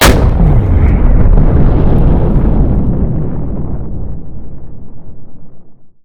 combat / vehicles / rocket.wav
rocket.wav